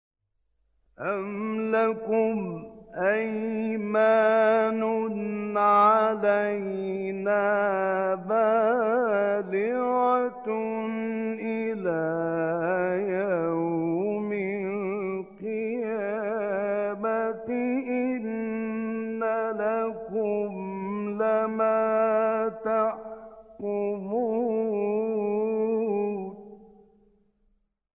گروه شبکه اجتماعی: مقاطعی صوتی از راغب مصطفی غلوش که در مقام حسینی اجرا شده است، می‌شنوید.
این مقاطع که در مقام حسینی اجرا شده‌اند، در زیر ارائه می‌شوند.